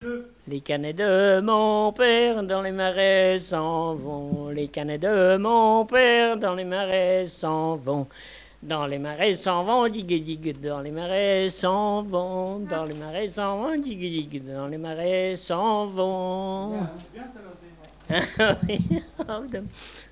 Mémoires et Patrimoines vivants - RaddO est une base de données d'archives iconographiques et sonores.
danse : branle : courante, maraîchine
Pièce musicale inédite